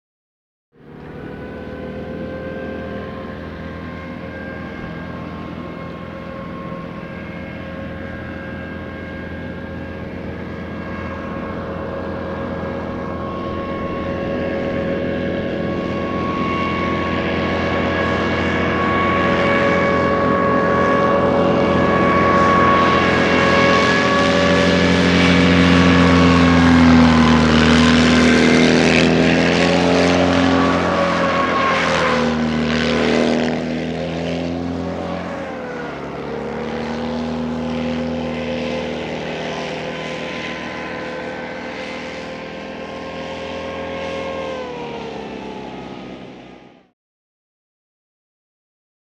Mini Hovercraft; By / In; Mini Hovercraft In And Past Mic. To Mid Shot, Then Circles Around In Mid Shot A Couple Of Times.